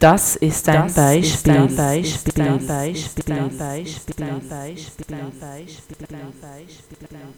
Jetzt ist auch im Mairlist selbst selber das Echo, wenn ich auf MIC klicke. Und dazu kommt noch so ein Geräusch.
Was du da hörst, ist nicht “irgendein Echo” sondern eine regelrechte Rückkopplung.